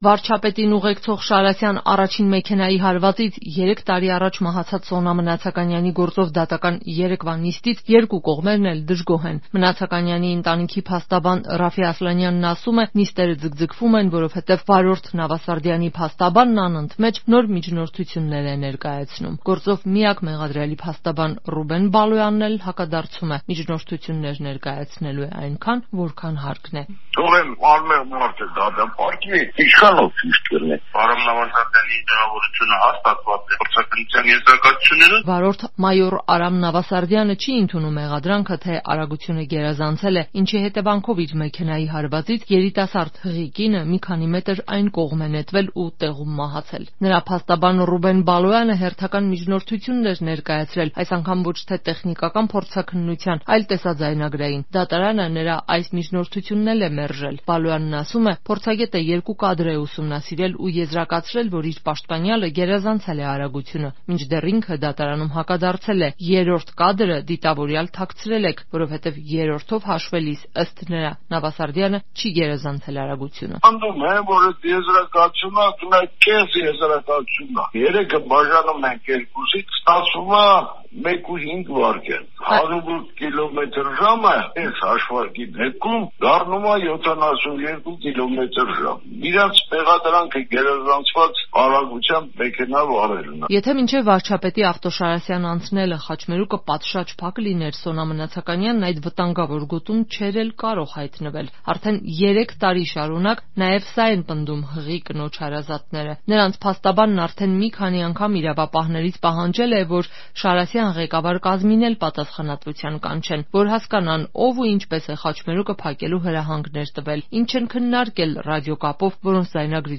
Ամբողջական տարբերակը՝ «Ազատության» ռեպորտաժում.